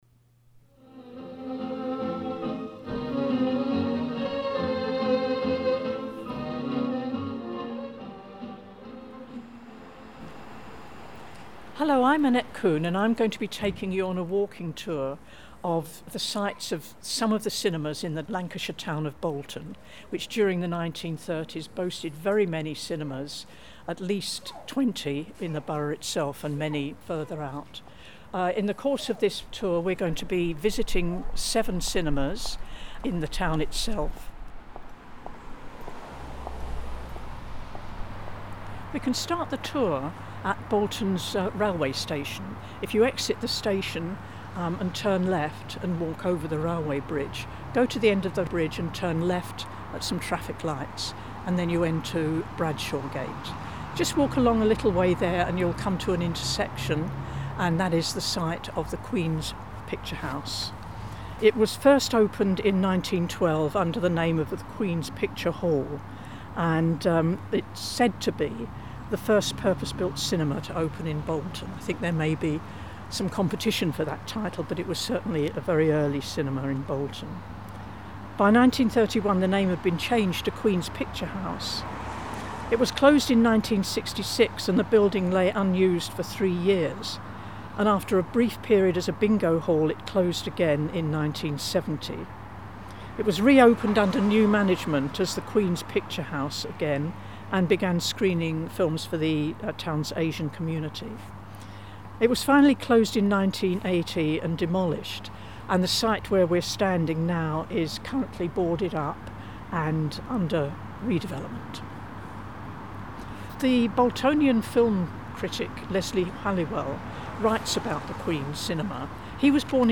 Audio tour around locations of 1930s Bolton cinemas - opens in new tab
Bolton_AudioTour.mp3